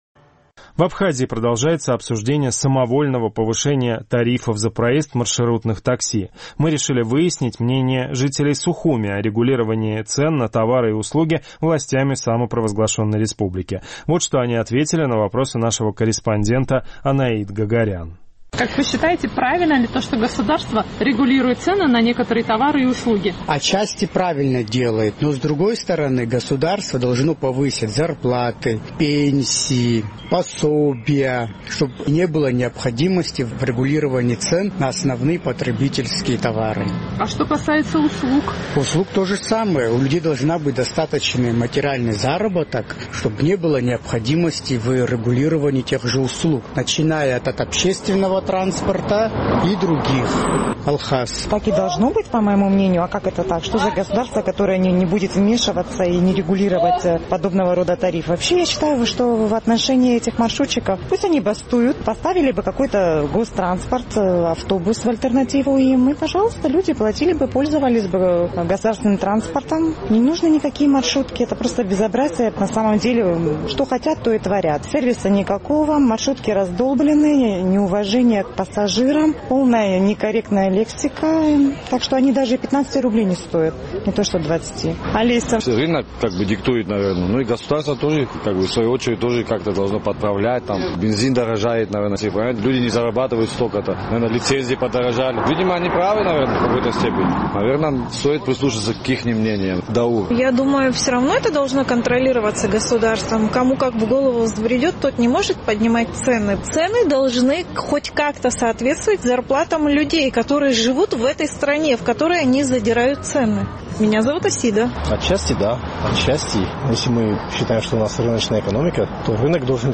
В Абхазии продолжается обсуждение самовольного повышения тарифов за проезд в маршрутных такси. Наш сухумский корреспондент решила выяснить мнение местных жителей о регулировании властями цен на товары и услуги.